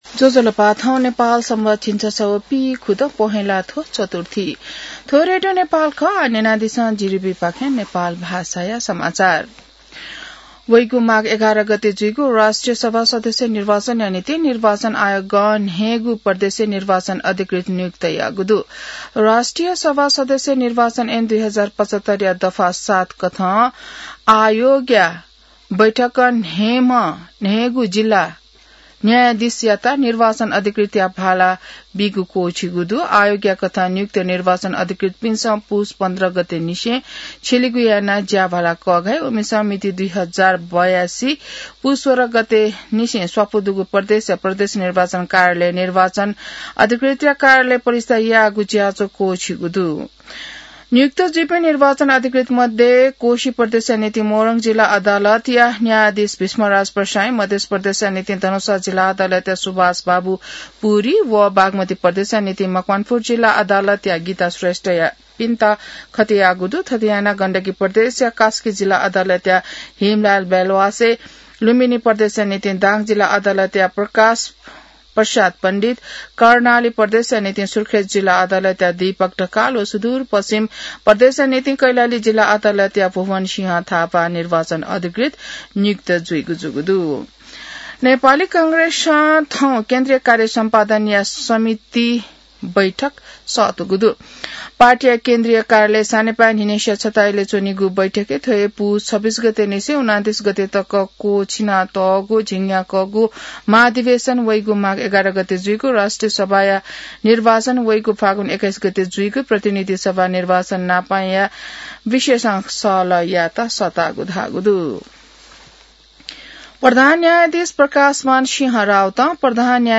नेपाल भाषामा समाचार : ९ पुष , २०८२